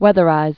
(wĕthə-rīz)